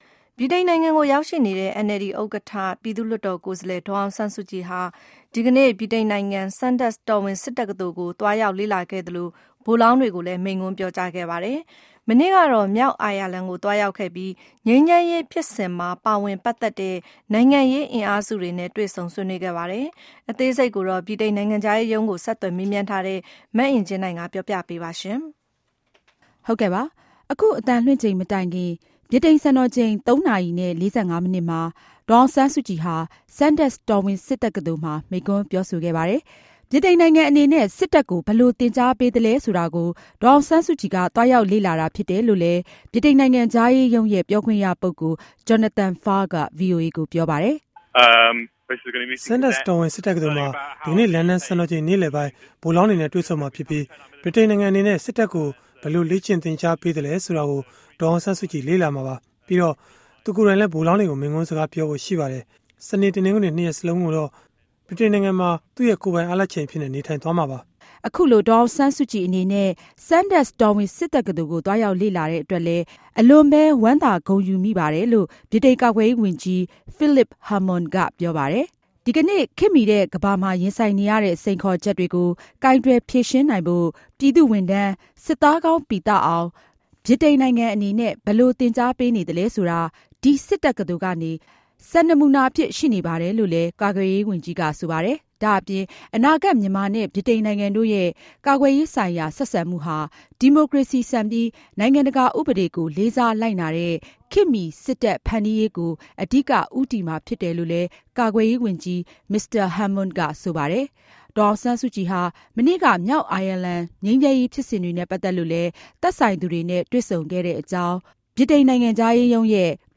ဒေါ်အောင်ဆန်းစုကြည် တော်ဝင်စစ်တက္ကသိုလ် မိန့်ခွန်း